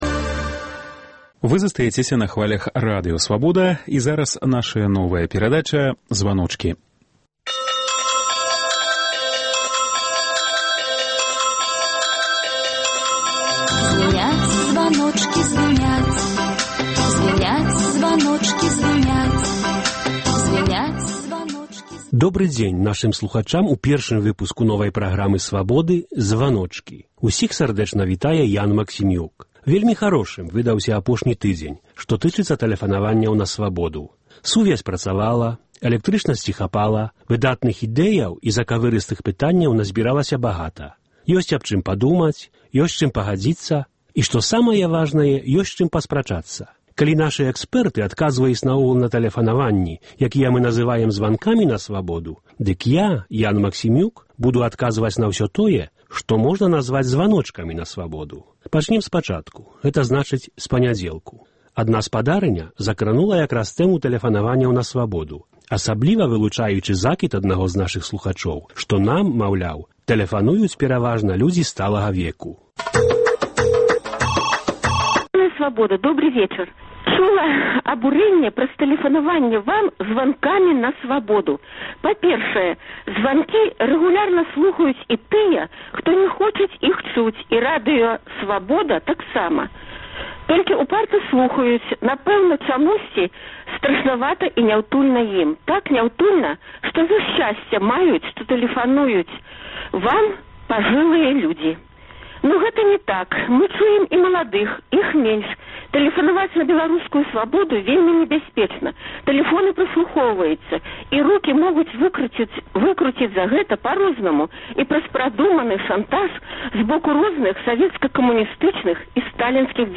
Вось праблемы, якія хвалююць нашых слухачоў у званках на "Свабоду".